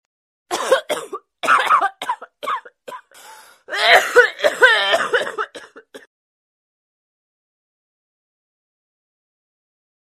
без слов
на смс
кашель